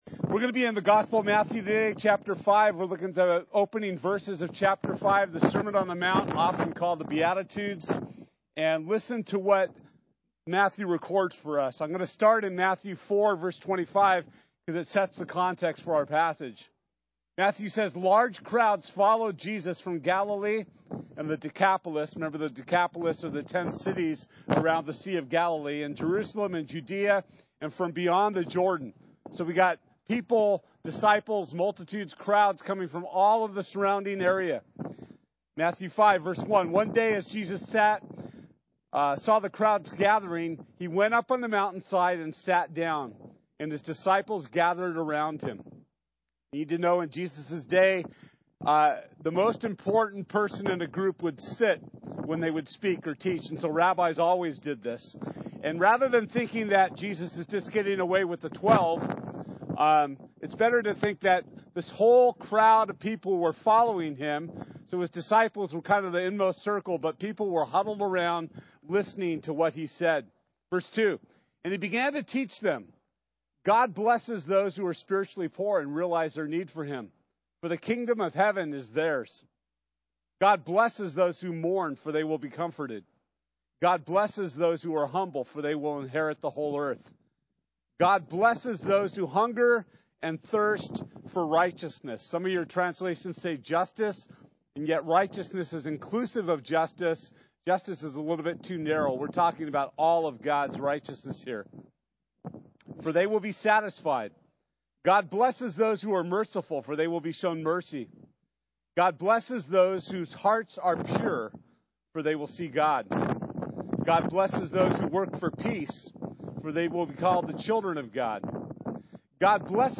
Matthew 5:1-16 Service Type: Sunday This Sunday we’ll be studying Matthew 5:1-16.